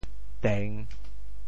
订（訂） 部首拼音 部首 言 总笔划 9 部外笔划 2 普通话 dìng 潮州发音 潮州 dêng3 文 潮阳 dêng3 文 澄海 dêng3 文 揭阳 dêng3 文 饶平 dêng3 文 汕头 dêng3 文 中文解释 潮州 dêng3 文 对应普通话: dìng ①改正，修改：～正 | 考～ | 校（jiào ）～ | 修～。